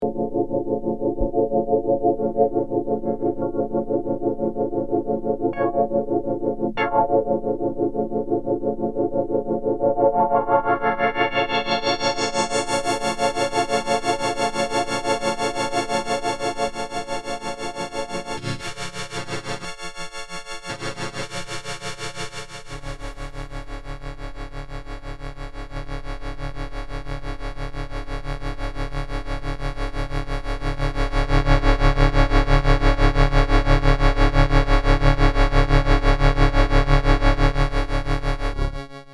VCF cutoff